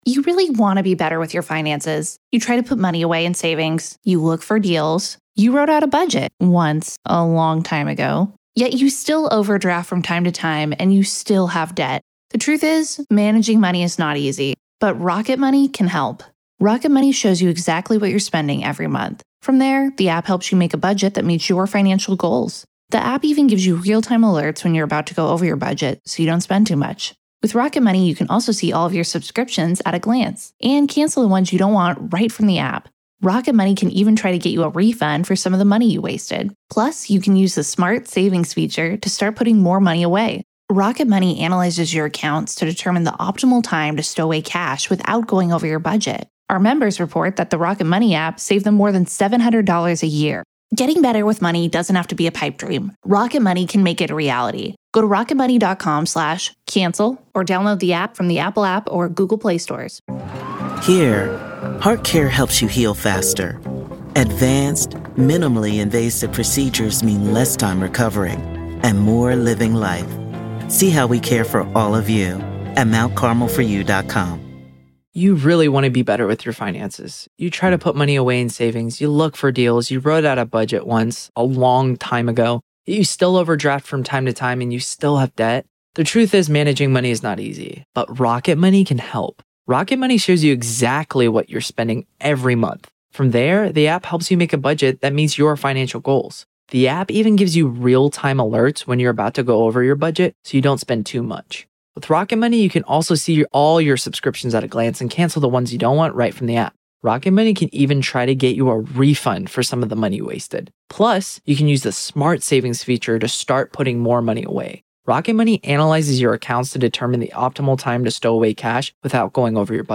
During Friday's session, emotional testimonies from the victims' families were anticipated, aiming to provide the jury with a deeper understanding of the devastating impact of Daybell's actions.